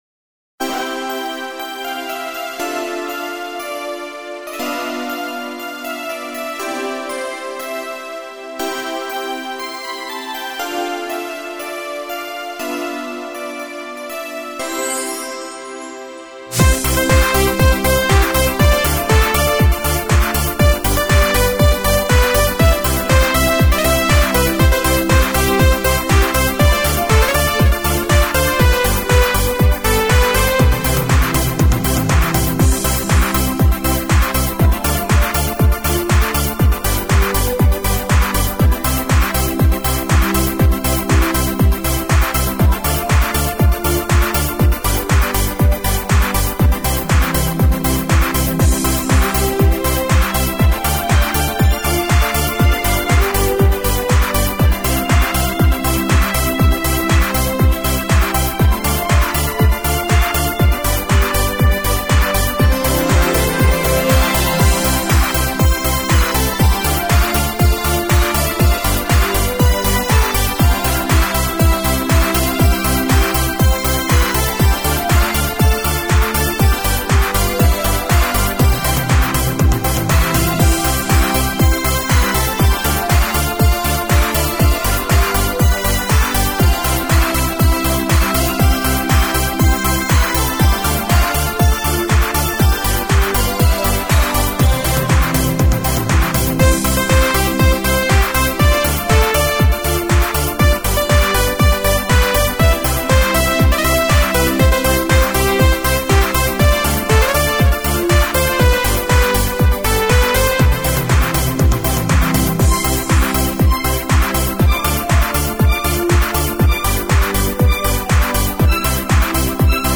Минусовки: